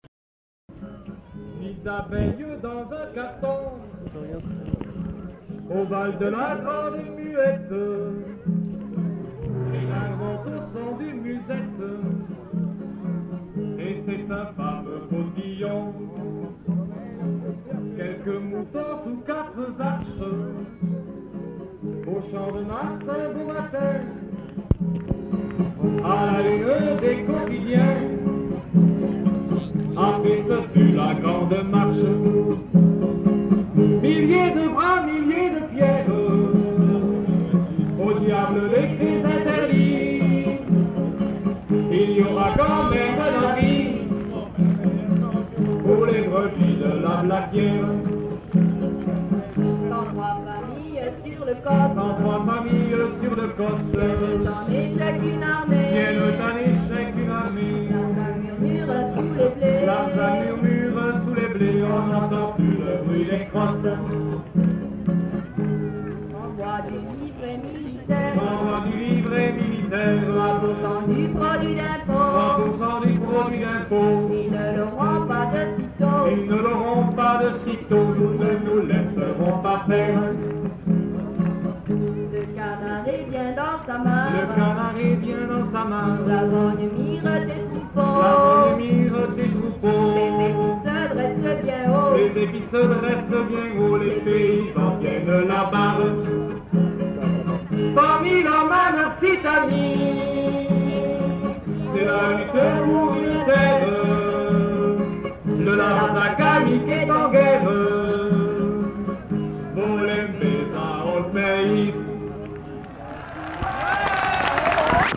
Lieu : [sans lieu] ; Aveyron
Genre : chanson-musique
Type de voix : voix d'homme ; voix de femme
Production du son : chanté
Instrument de musique : guitare